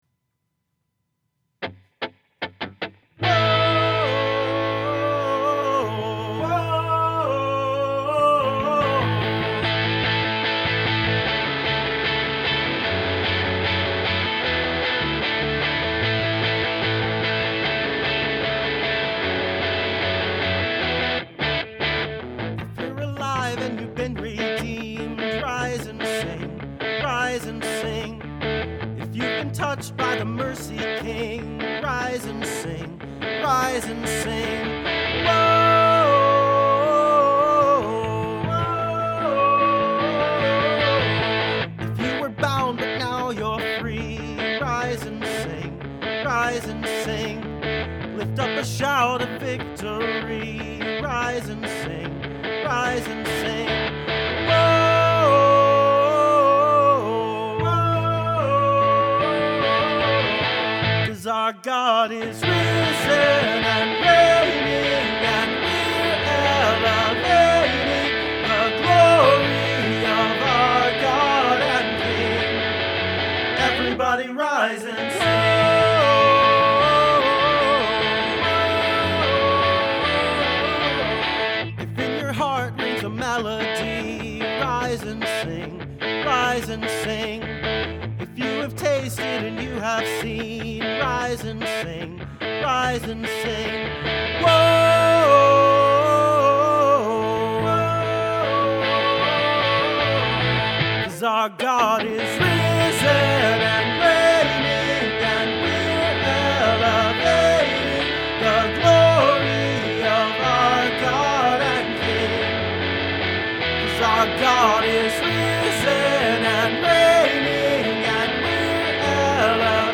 This song is a little difficult, rhythmically, but it could always be worse.
extra vox, electric guitar and bass
For the backing vocals, I used two mics on opposite ends of the room, singing closer to one, but getting two tracks out of it.